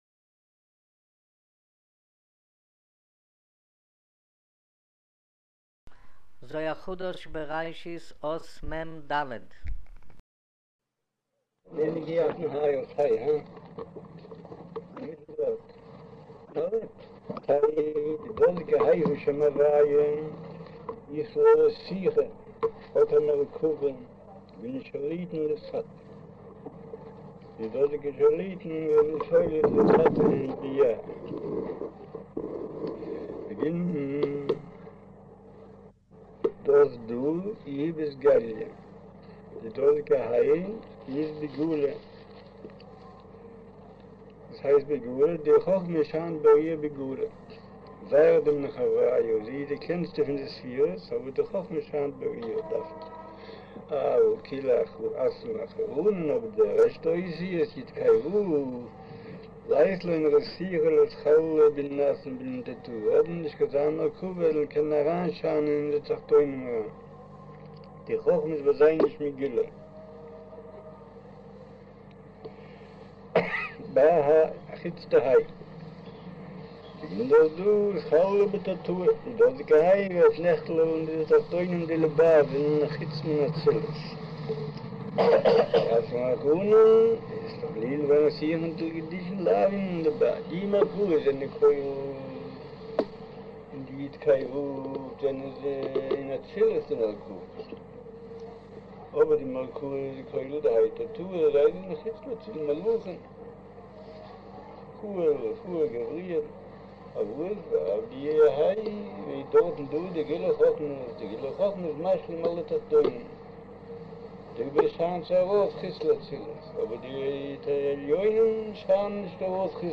אודיו - שיעור מבעל הסולם זהר חדש בראשית אות מד' - מט'